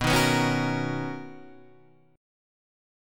BM9 chord